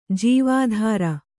♪ jīvādhāra